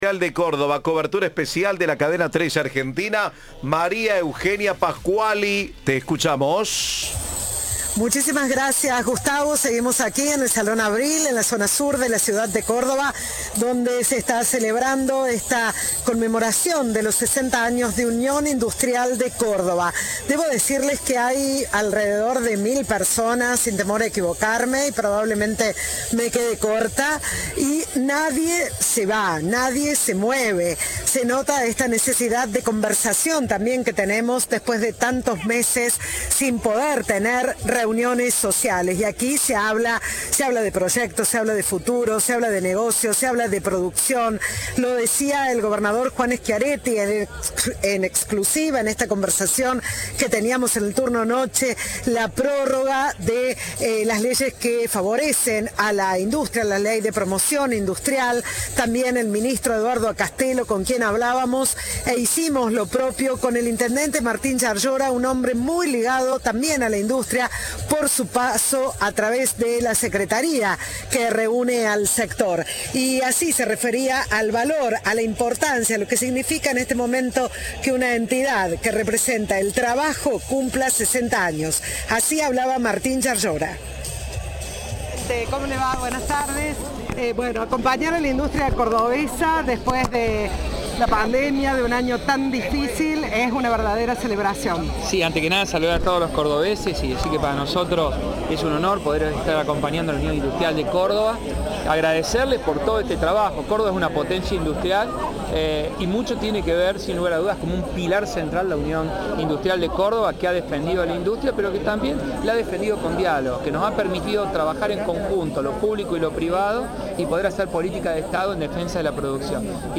Schiaretti participó de la fiesta por el 60 aniversario de la Unión Industrial de Córdoba (UIC), que se hizo en el Salón Avril de la capital cordobesa.